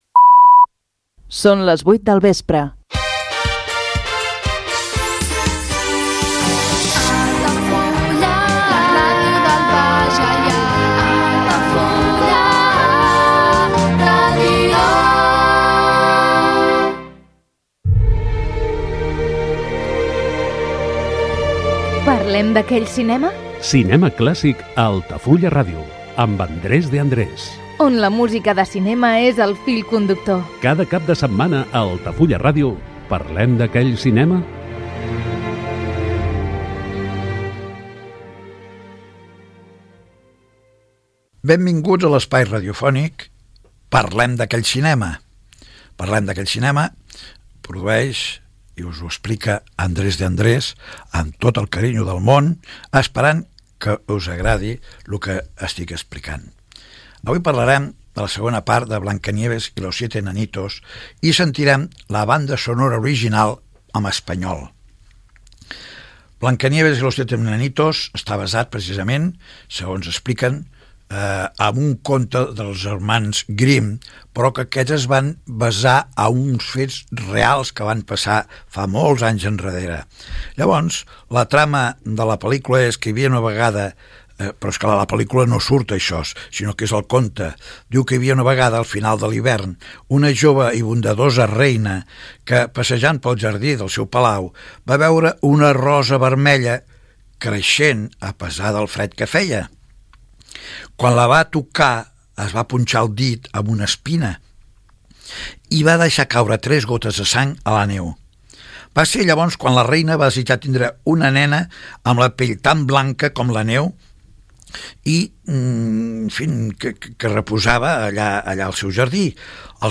La música de cinema és el fil conductor de l’espai